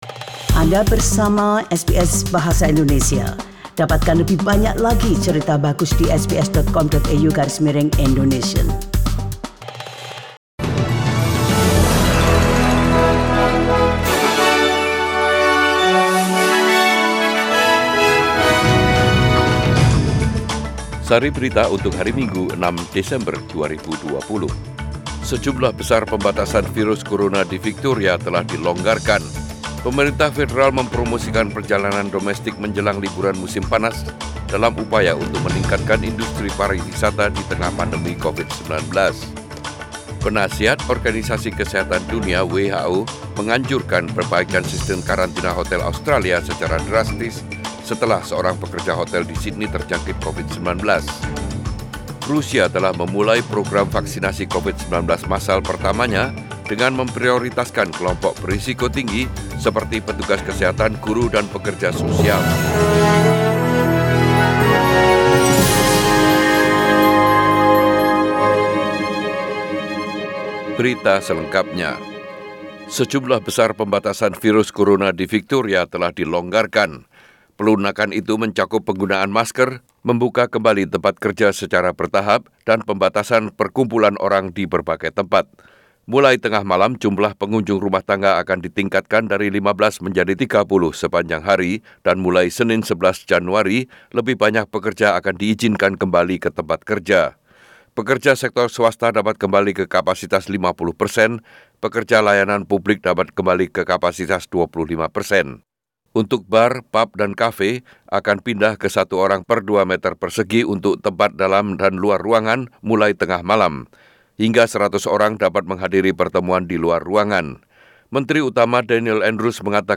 SBS Radio News in Bahasa Indonesia - 6 December 2020